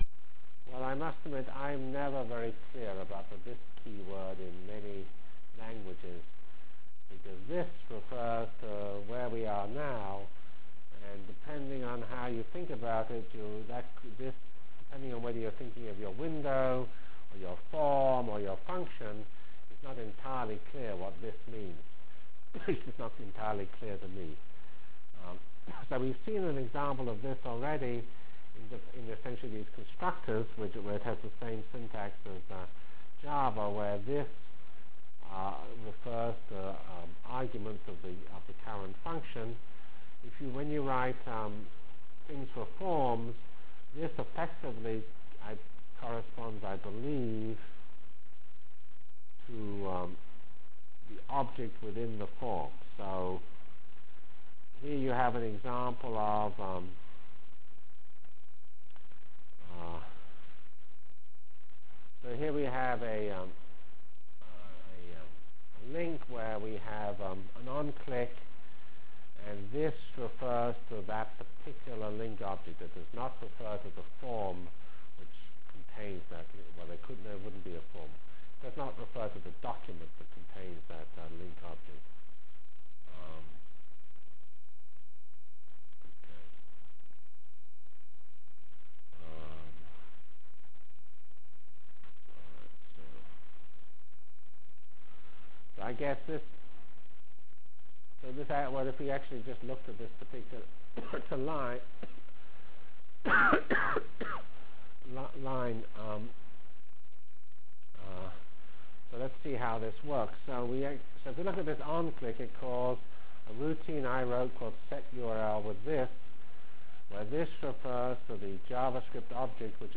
From Feb 17/19 Delivered Lecture for Course CPS616 -- aJavaScript Language Objects and Frames CPS616 spring 1997 -- Feb 17 and 19 1997.